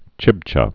(chĭbchə)